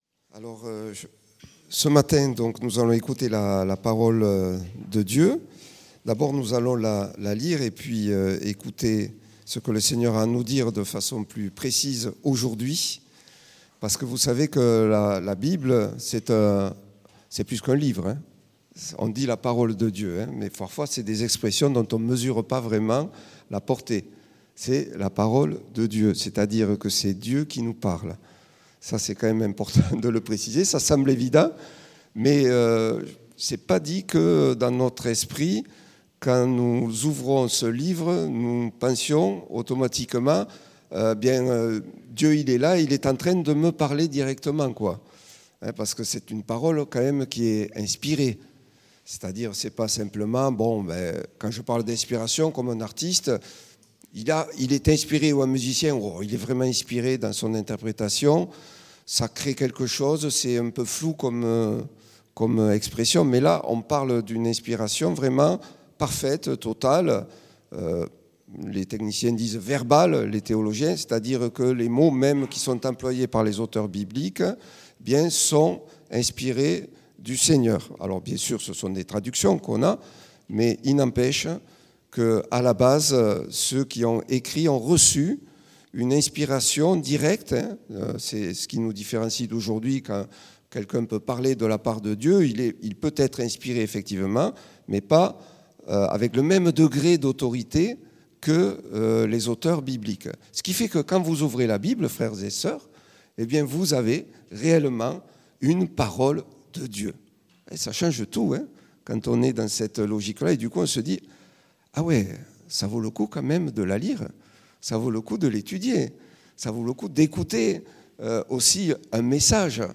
Predication-260308.mp3